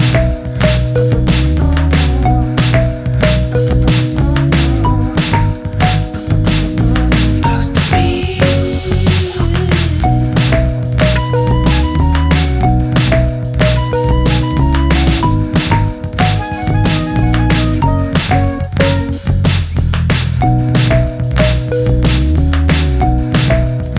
no Backing Vocals Indie / Alternative 3:37 Buy £1.50